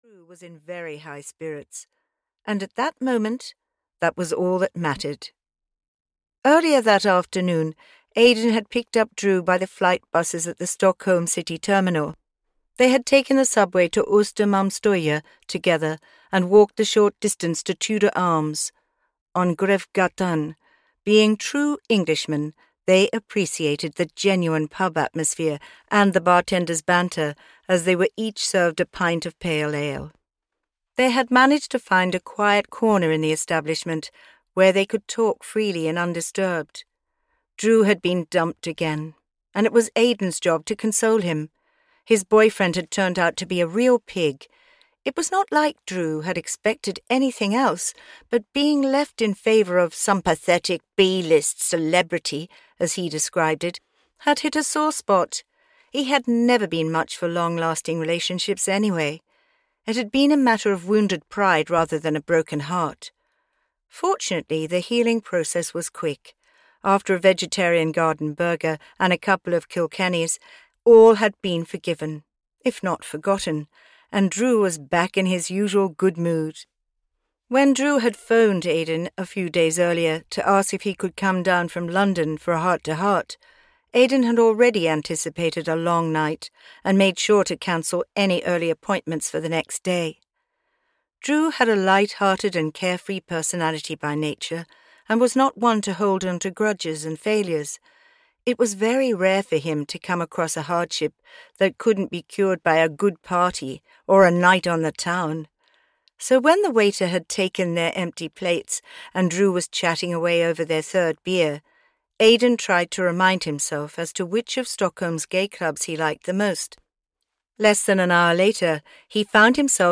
Because You’re Mine (EN) audiokniha
Ukázka z knihy